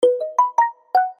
Короткие рингтоны , Рингтоны на смс и уведомления